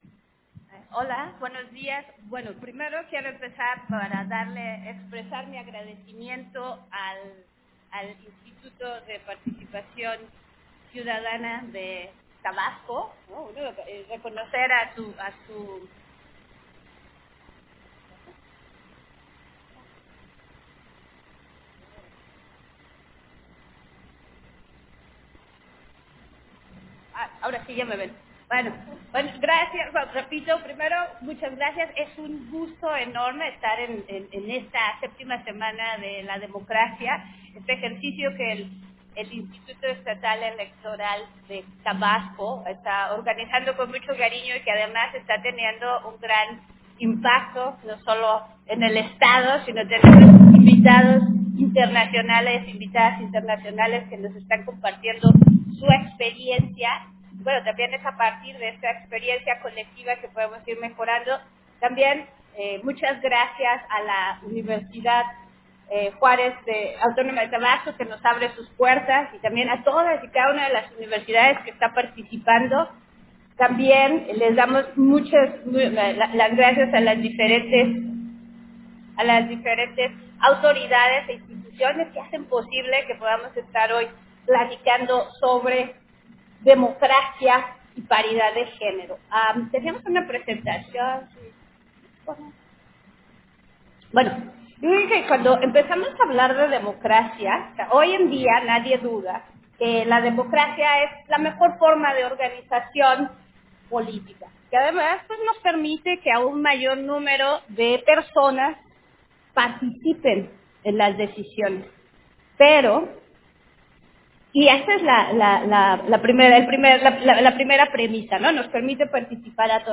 Versión estenográfica de al Conferencia, Democracia y paridad de género, que impartió la Consejera Norma de La Cruz